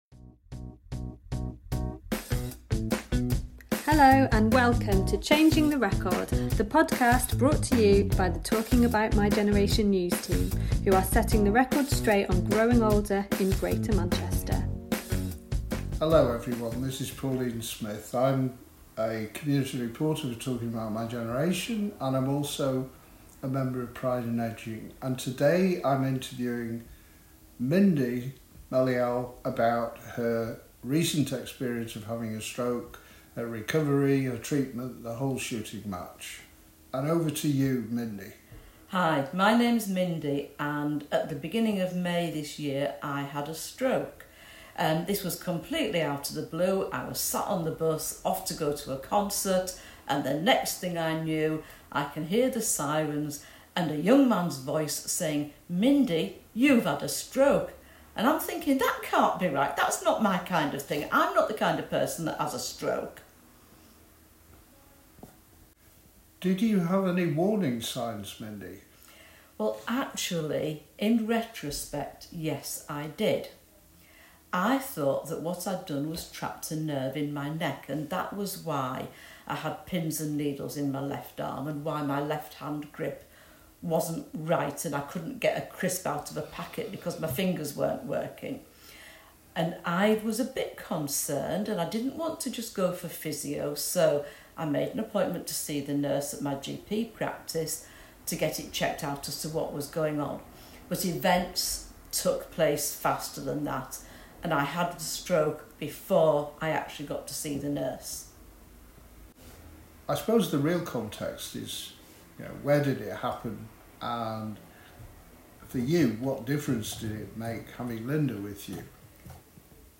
chats with friend